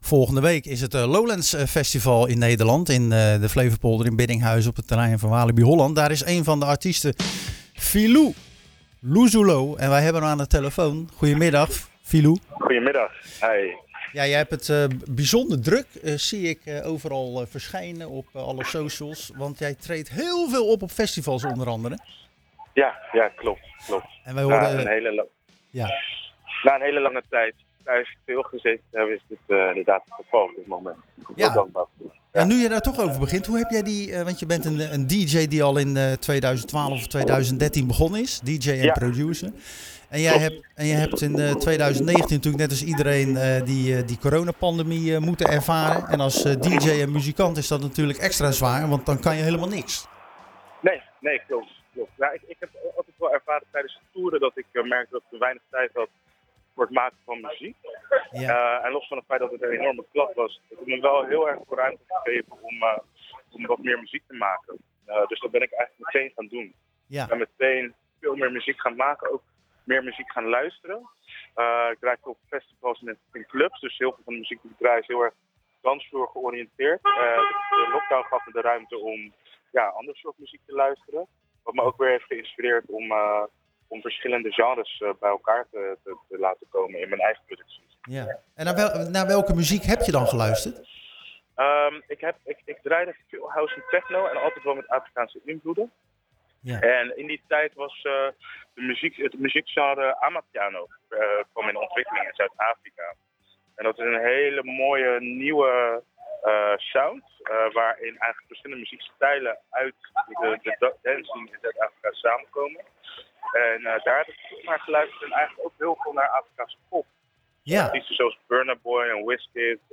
Als vooruitblik op het Lowlandsfestival spraken we tijdens de festivaluitzending van Zwaardvis met de Rotterdamse DJ en producer